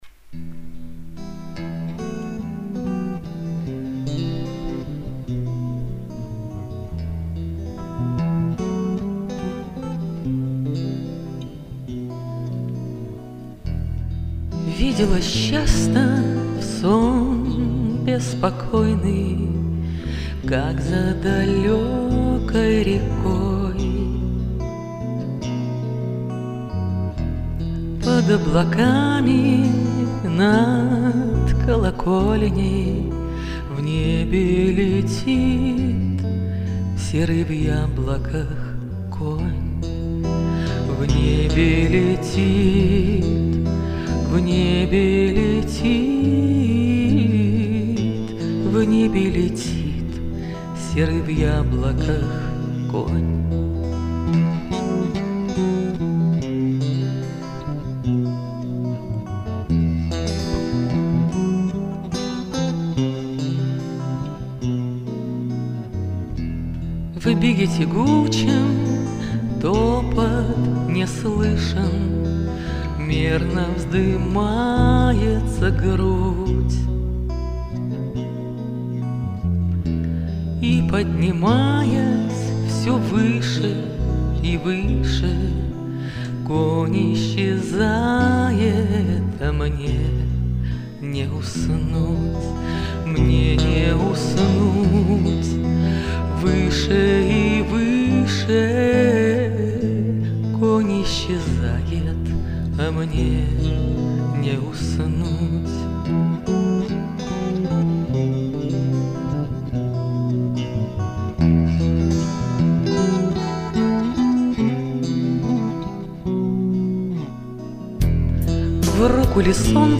А в колонках,ваще-вкуууусно!!!pocelujchik